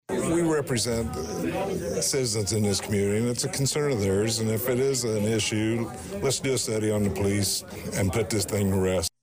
A couple Danville alderman stated during the chief’s Tuesday presentation that that’s why a special study should be done; to try and reach a conclusion on who’s right and wrong about what in all this.